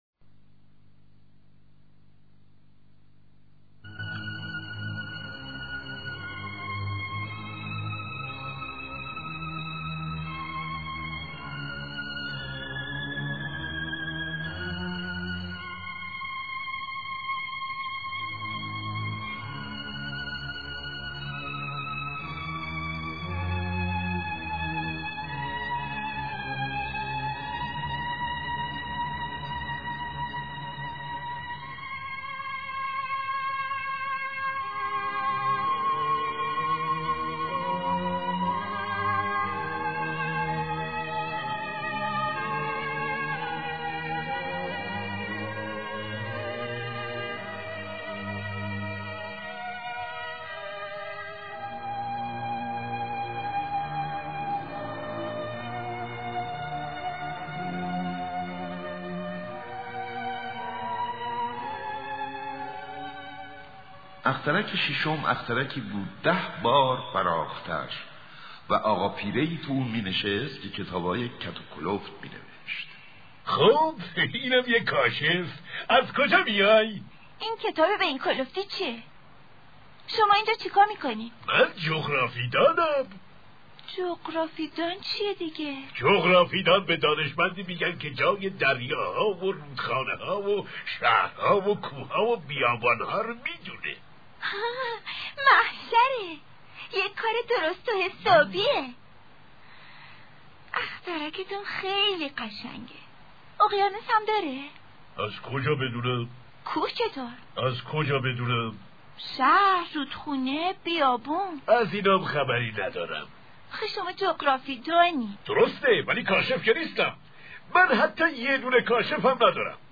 آنتوان دوسنت اگزوپری (شازده کوچولو "ترجمه و صدای احمد شاملو")